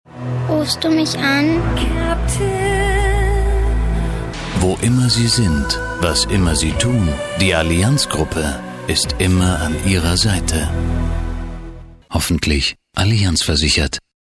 Allianz TV-Spot Audio Download
AllianzVersicherungTVSpot.mp3